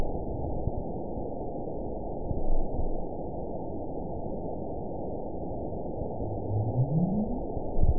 event 919856 date 01/26/24 time 02:06:52 GMT (1 year, 3 months ago) score 9.59 location TSS-AB03 detected by nrw target species NRW annotations +NRW Spectrogram: Frequency (kHz) vs. Time (s) audio not available .wav